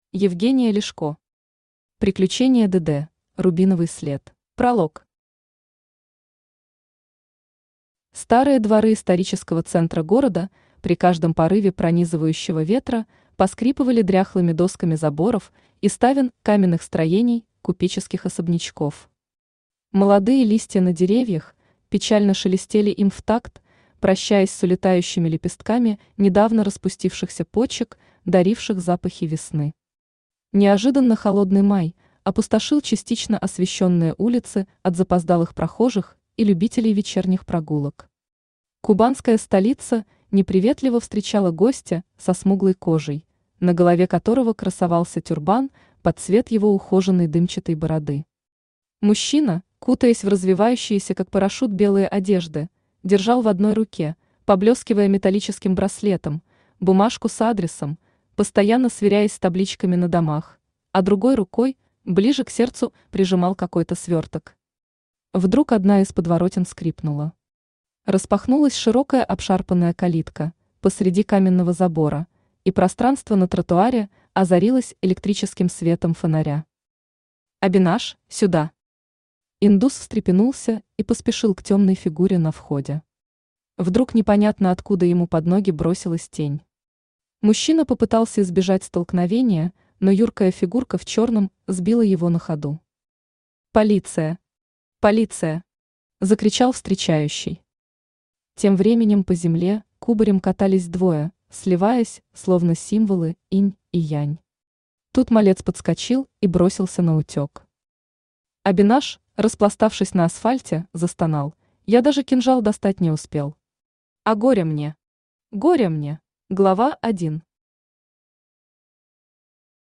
Читает: Авточтец ЛитРес
Аудиокнига «Приключения ДД. Рубиновый след».